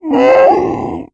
Скачивайте рыки, рев, тяжелое дыхание и крики фантастических существ в формате MP3.